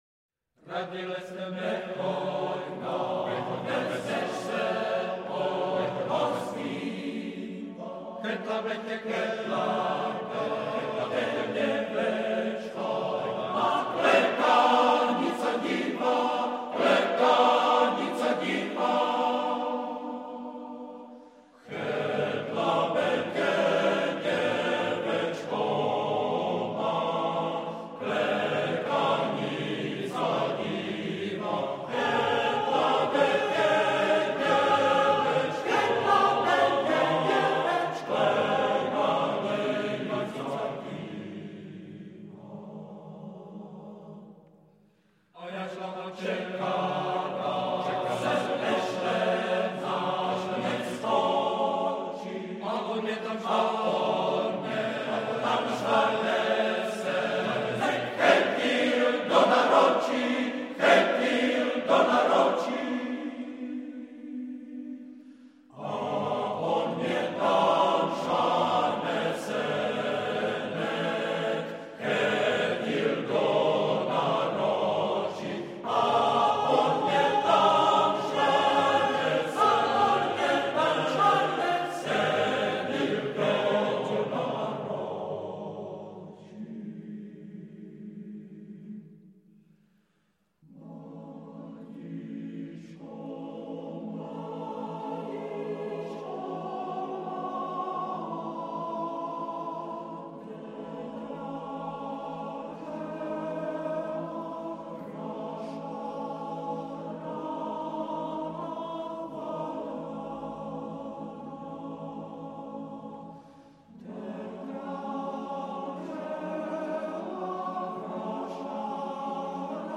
FF:VH_15b Collegium musicum - mužský sbor